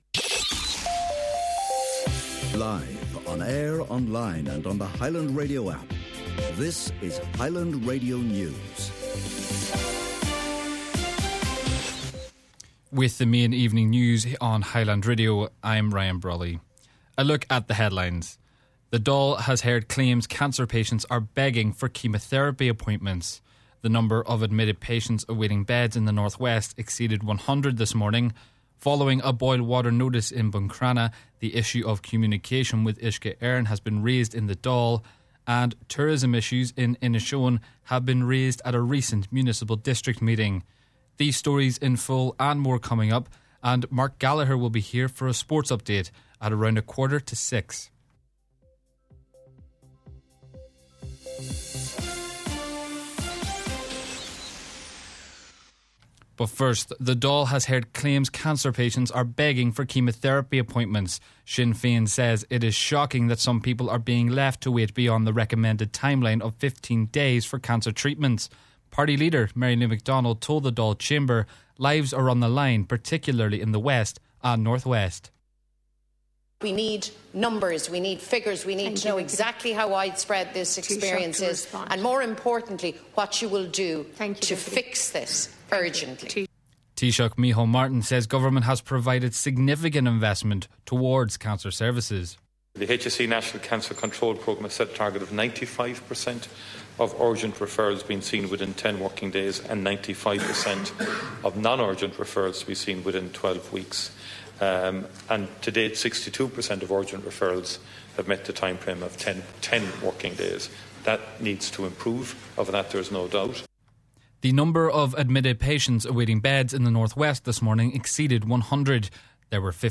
Main Evening News, Sport and Obituary Notices – Wednesday January 14th